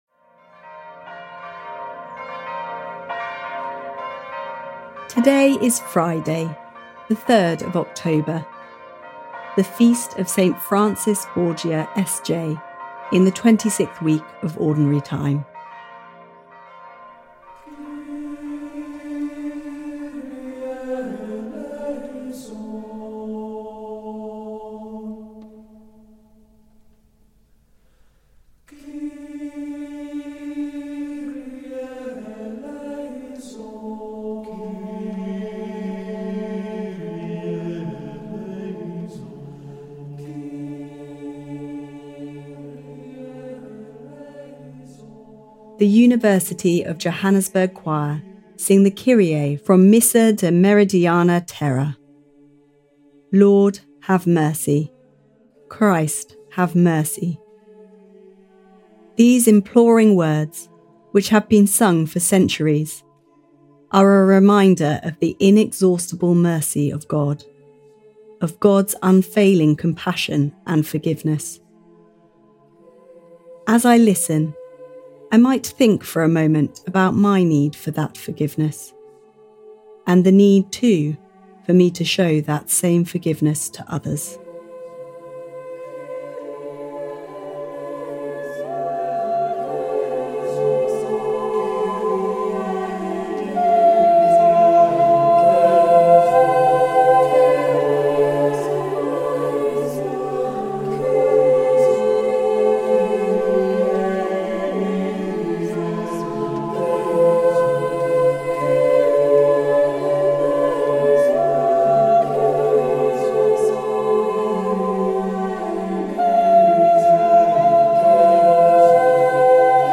The University of Johannesburg Choir sing the Kyrie from Missa De Meridiana Terra: Lord, have mercy; Christ, have mercy. These imploring words, which have been sung for centuries, are a reminder of the inexhaustible mercy of God, of God’s unfailing compassion and forgiveness.